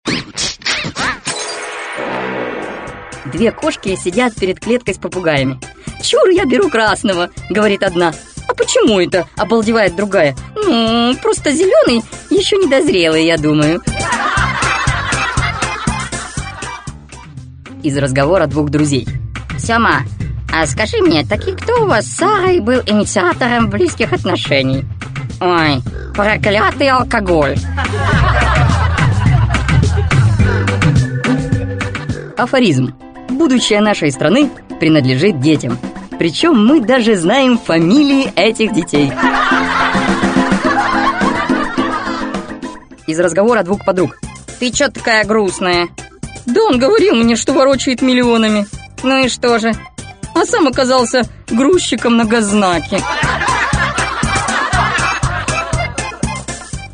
Аудиокнига Сборник анекдотов. Выпуск 1 | Библиотека аудиокниг